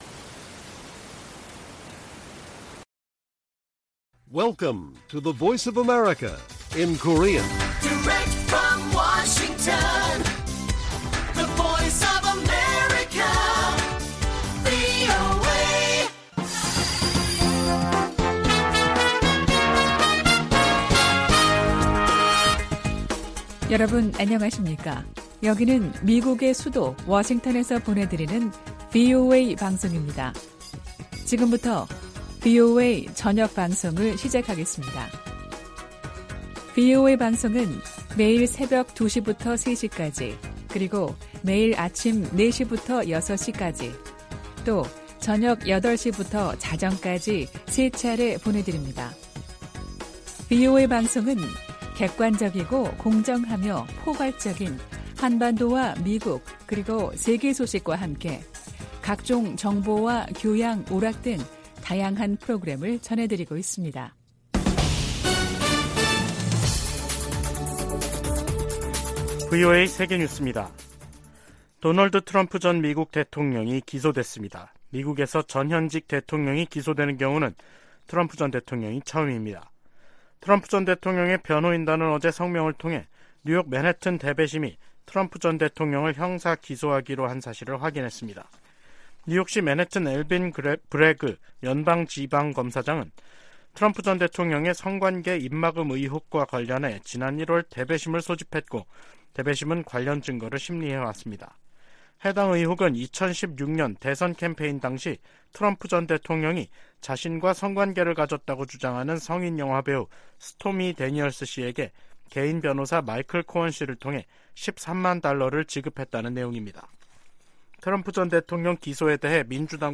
VOA 한국어 간판 뉴스 프로그램 '뉴스 투데이', 2023년 3월 31일 1부 방송입니다. 미 재무부가 북한과 러시아의 무기 거래에 관여한 슬로바키아인을 제재 명단에 올렸습니다. 백악관은 러시아가 우크라이나 전쟁에서 사용할 무기를 획득하기 위해 북한과 다시 접촉하고 있다는 새로운 정보가 있다고 밝혔습니다. 미 국방부는 핵무기 한국 재배치 주장에 한반도 비핵화 정책을 계속 유지할 것이라고 밝혔습니다.